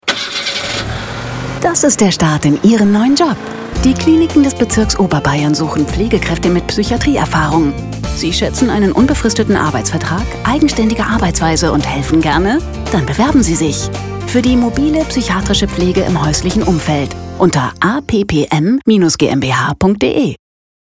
Der eigens produzierte Funk-Spot aktiviert die Bewerber sowohl im klassischen Radio-Programm als auch im Webradio.